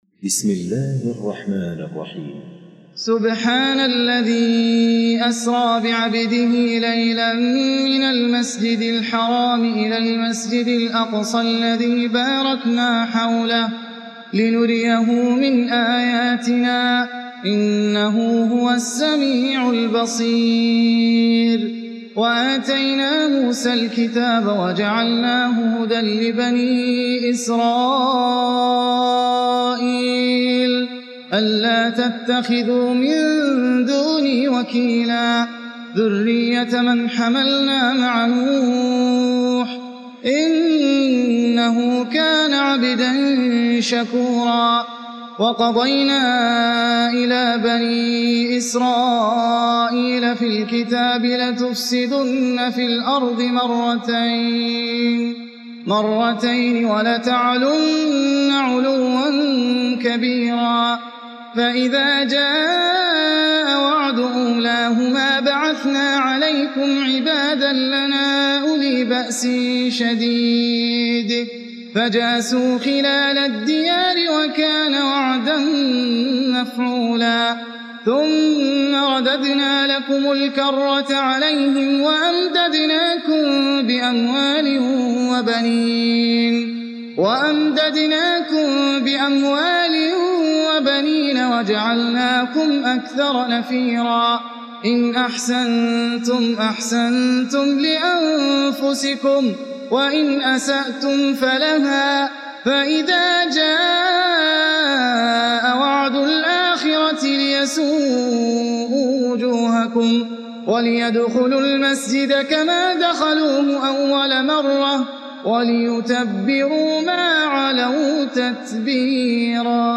سورة الاسراء (الختمة مرتلة) - إنجليزي